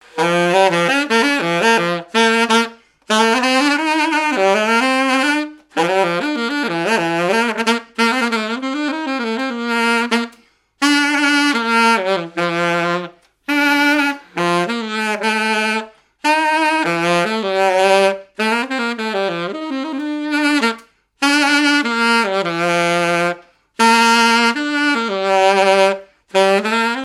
circonstance : fiançaille, noce
activités et répertoire d'un musicien de noces et de bals
Pièce musicale inédite